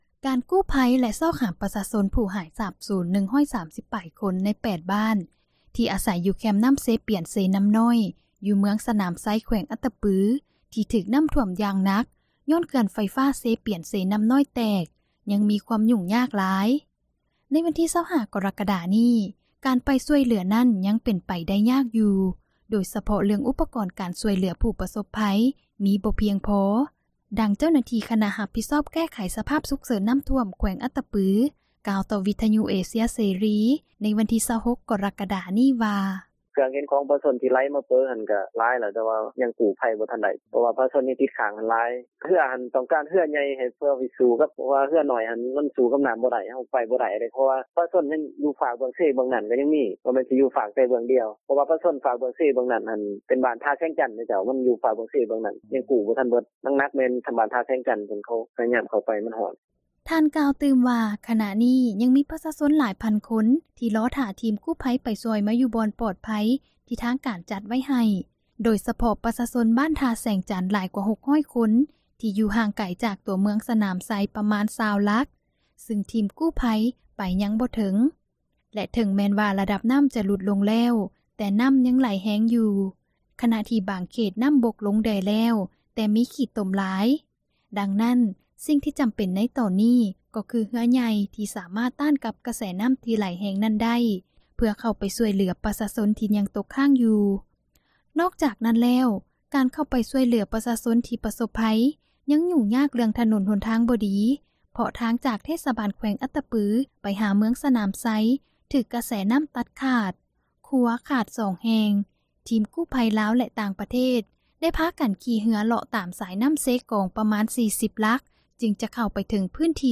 ດັ່ງເຈົ້າໜ້າທີ່ ຄນະຮັບຜິດຊອບ ແກ້ໄຂສະພາບສຸກເສີນນໍ້າຖ້ວມ ແຂວງອັດຕະປື ກ່າວຕໍ່ວິທຍຸເອເຊັຽເສຣີ ໃນວັນທີ 26 ກໍຣະກະດາ ນີ້ວ່າ: